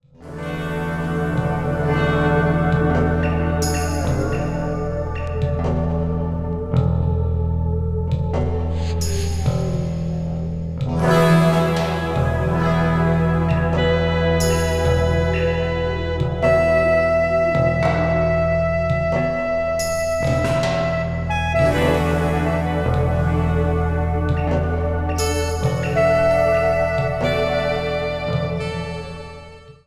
ambient   contemporary   electronic   new age   synthesizer